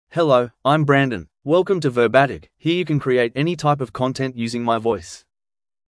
Brandon — Male English (Australia) AI Voice | TTS, Voice Cloning & Video | Verbatik AI
MaleEnglish (Australia)
Brandon is a male AI voice for English (Australia).
Voice sample
Brandon delivers clear pronunciation with authentic Australia English intonation, making your content sound professionally produced.